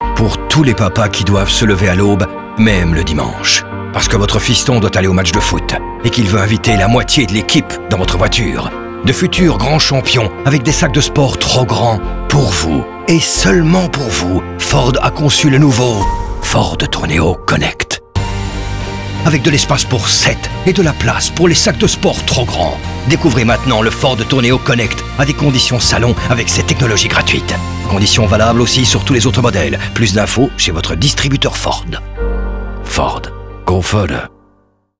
In twee epische radiospots eert Ford op heroïsche wijze alle mama’s en papa’s die elke woensdagnamiddag en in het weekend van hot naar her moeten rijden. De logische boodschap is natuurlijk dat de Ford Tourneo Connect de perfecte partner is om in dat wekelijkse opzet te slagen.
Radio Production: Raygun